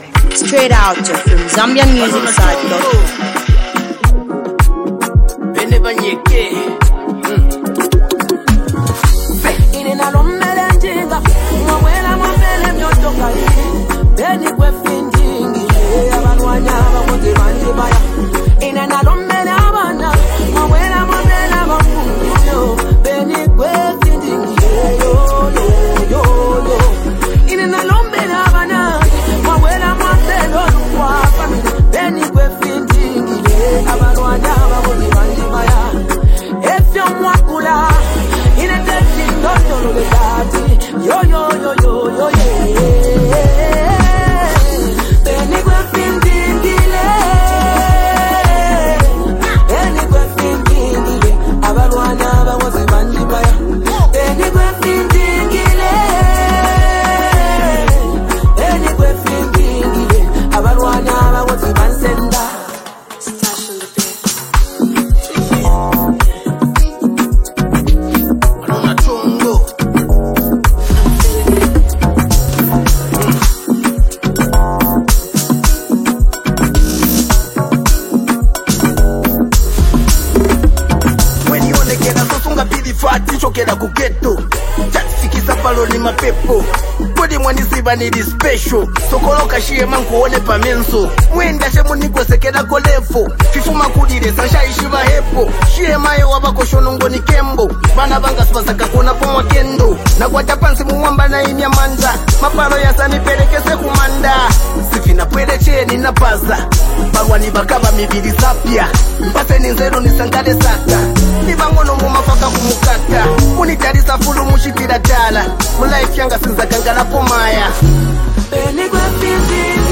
featuring a well known gospel artist